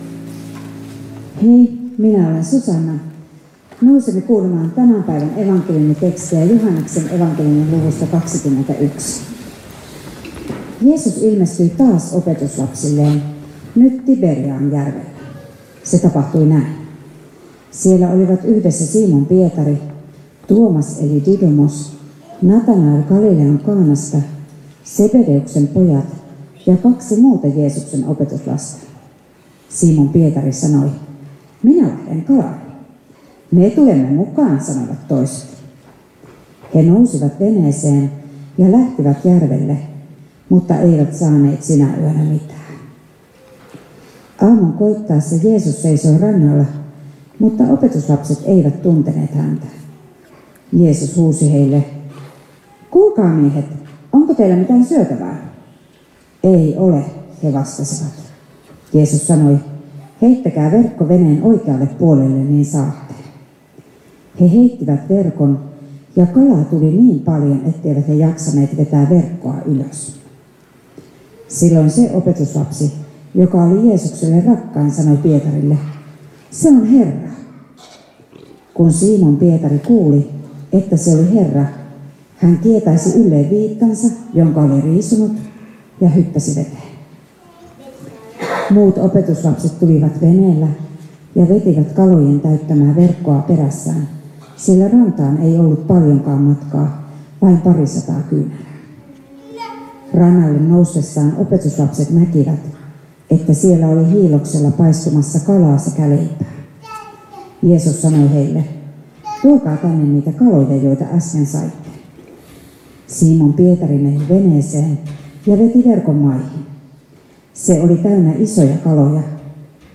Helsinki